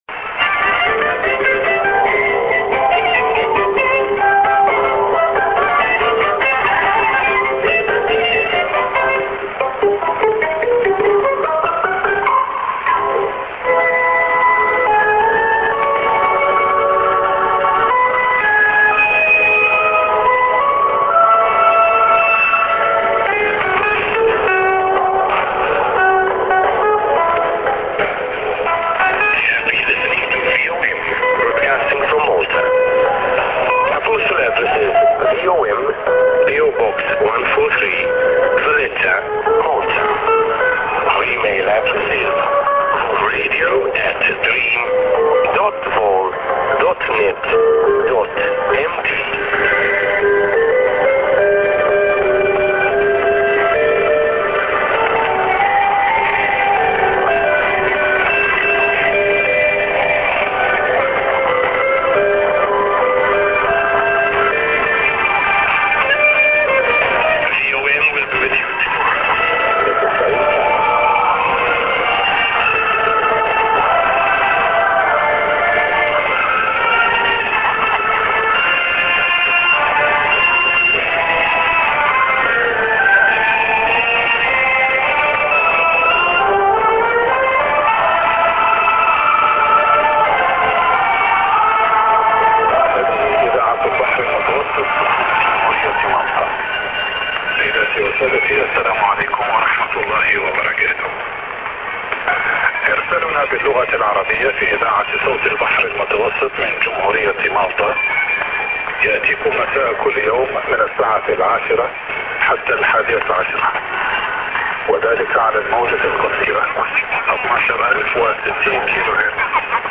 End music->ADDR+web ADDR(man)->